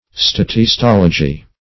Search Result for " statistology" : The Collaborative International Dictionary of English v.0.48: Statistology \Stat`is*tol"o*gy\ (st[a^]t`[i^]s*t[o^]l"[-o]*j[y^]), n. [Statistics + -logy.]